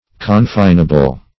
Search Result for " confinable" : The Collaborative International Dictionary of English v.0.48: Confinable \Con*fin"a*ble\, a. Capable of being confined, restricted, or limited.